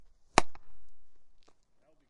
描述：1个快速手套接球。中投，中投。
Tag: 手套抓